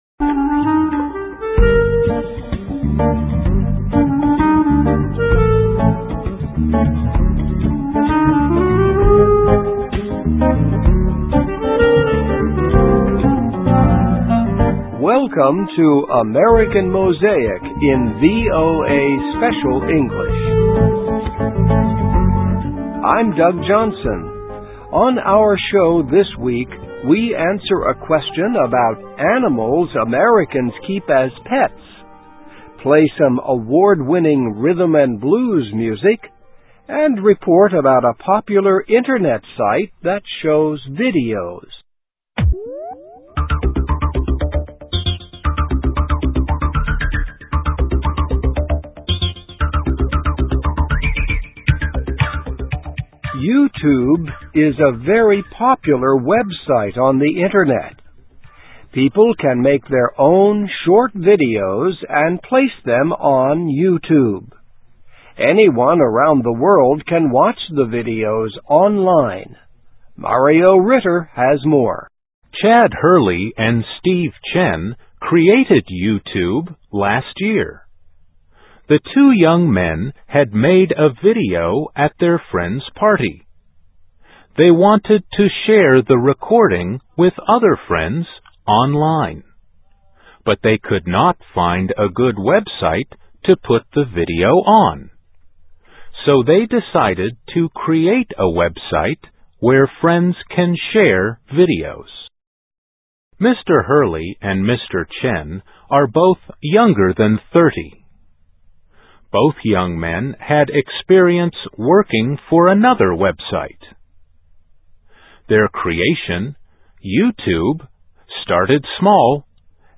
Listen and Read Along - Text with Audio - For ESL Students - For Learning English
Play some award winning rhythm and blues music …